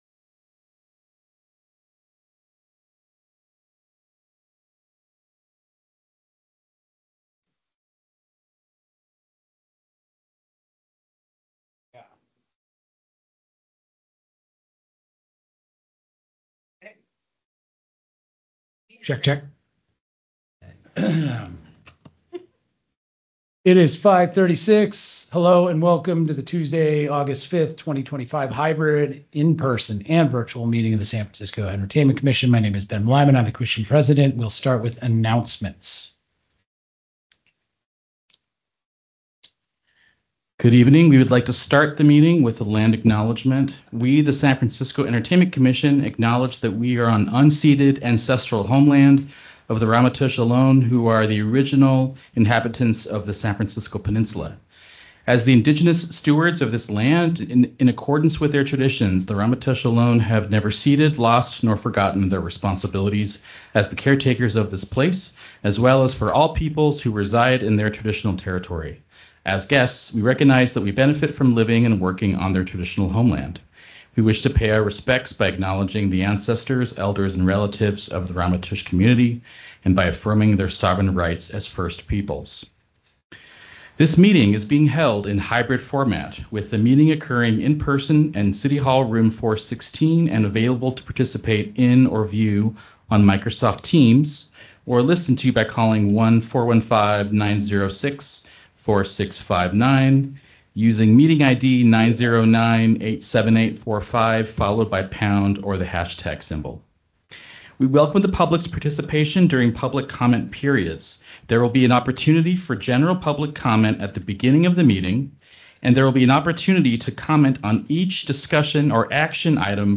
August 5, 2025 EC Meeting Recording - Aug 05, 2025